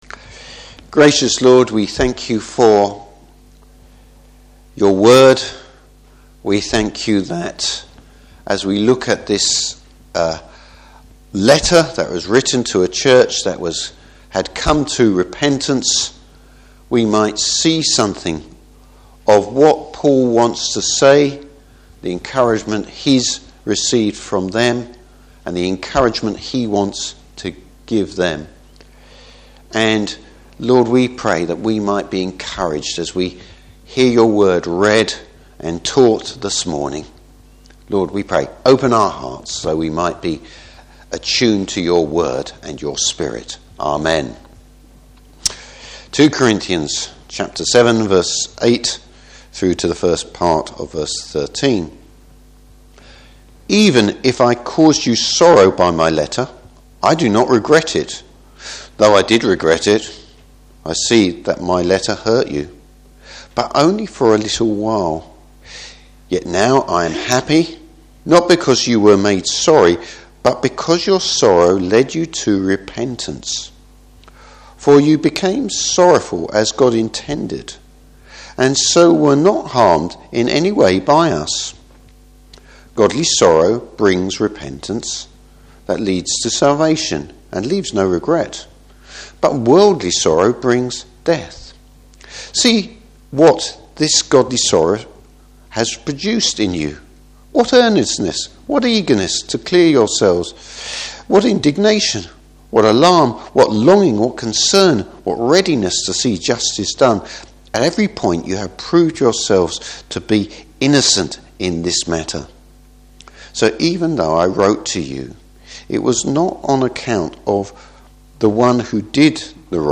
Service Type: Morning Service How the Corinthian’s repentant attituded was a major encouragement to Paul.